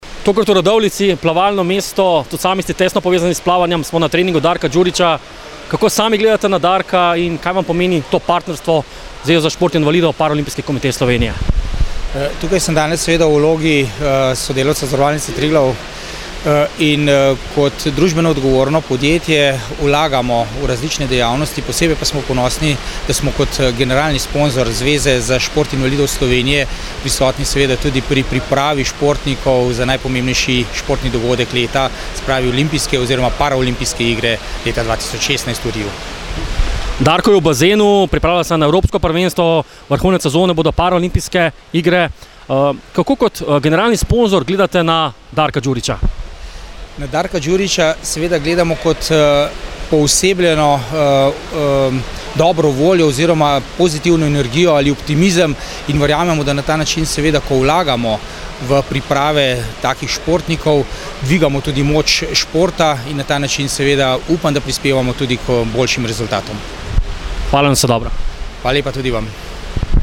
Na voljo so vam tudi avdio pogovori: